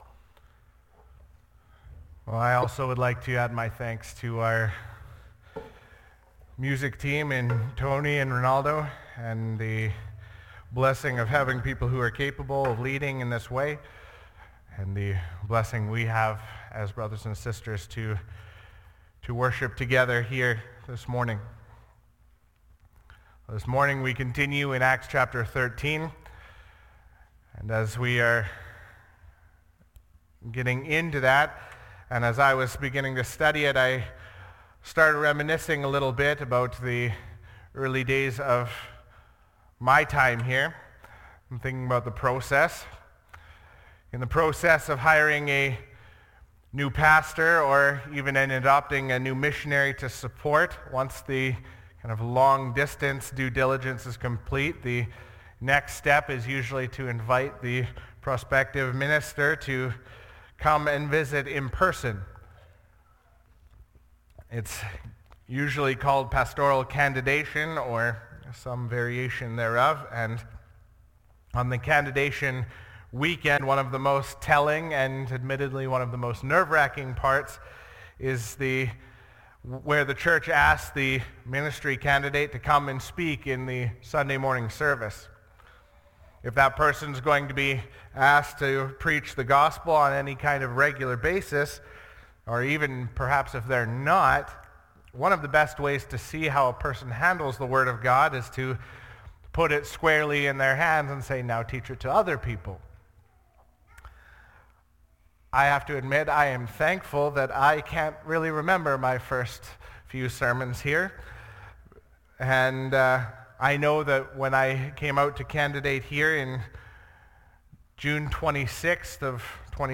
Sermons | Elk Point Baptist Church